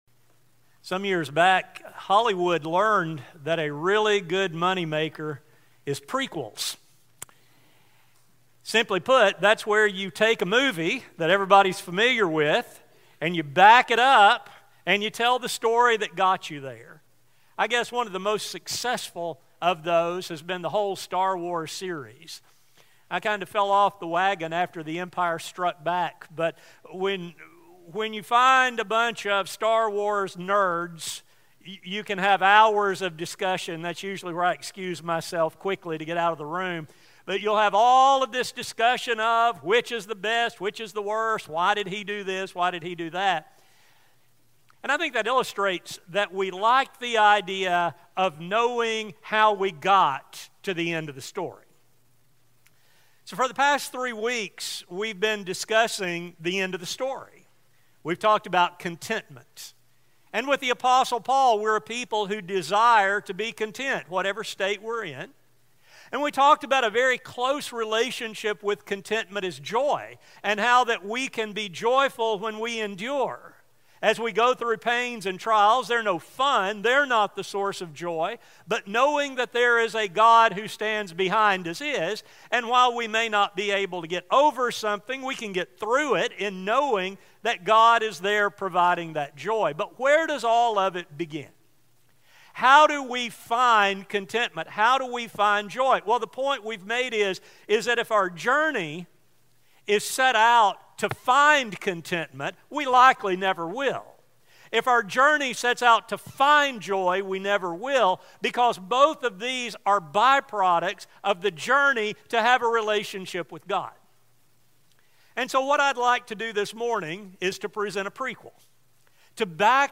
Through God's servant Philip, this confusion was corrected, resulting in salvation and joy. Like this desert traveler, everyone can come to know the joy of salvation. A sermon